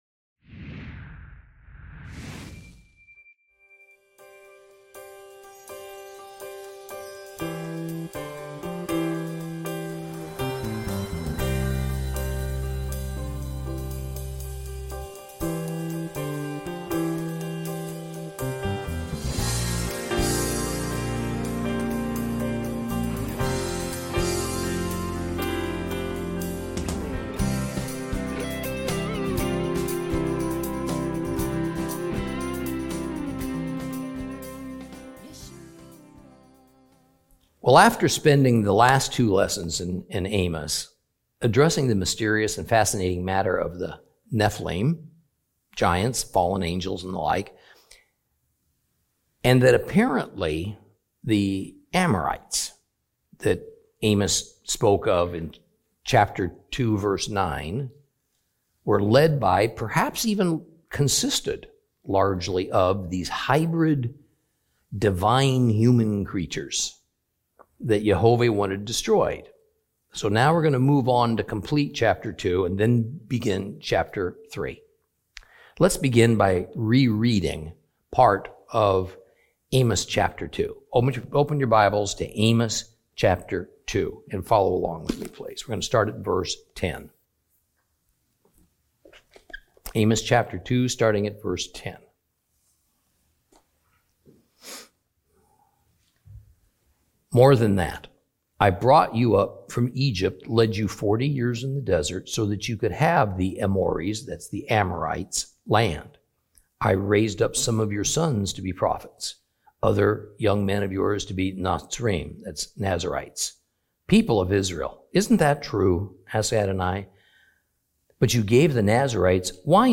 Teaching from the book of Amos, Lesson 6 Chapters 2 and 3.